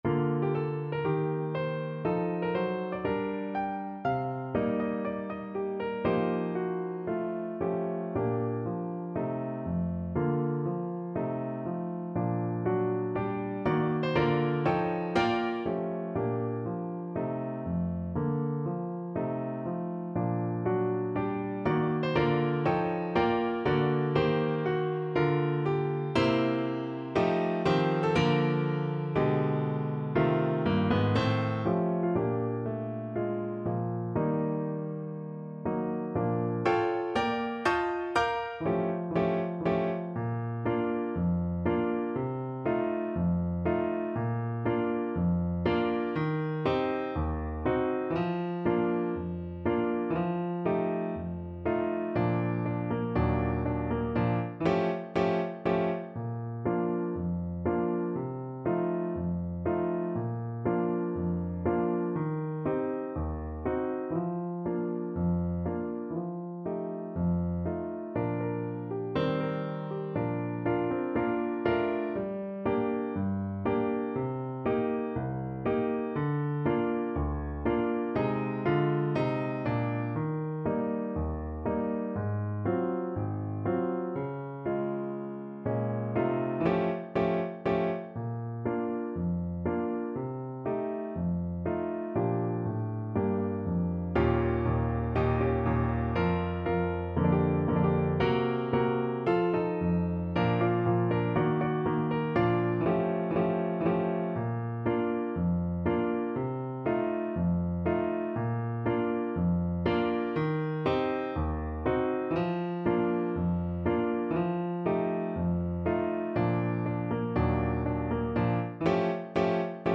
~ = 120 Moderato
4/4 (View more 4/4 Music)